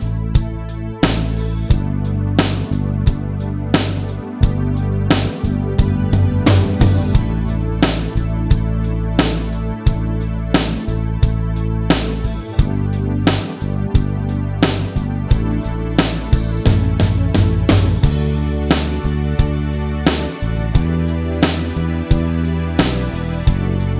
No Main Guitar Rock 5:15 Buy £1.50